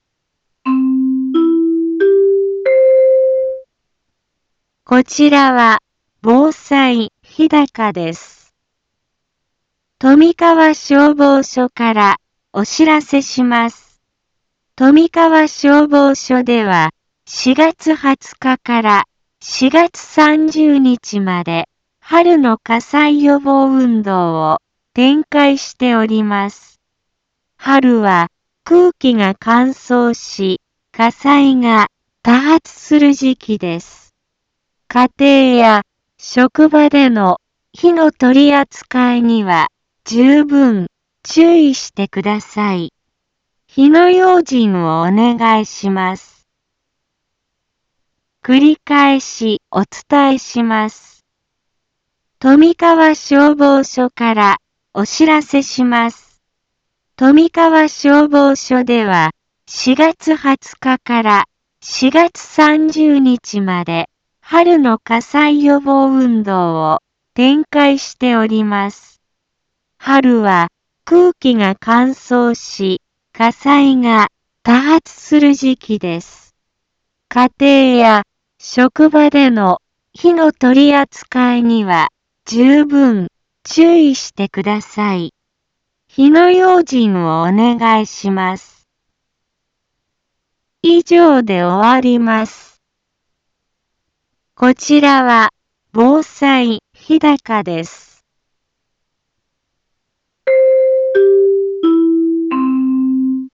一般放送情報
Back Home 一般放送情報 音声放送 再生 一般放送情報 登録日時：2021-04-20 15:03:40 タイトル：春の火災予防運動 インフォメーション：こちらは防災日高です。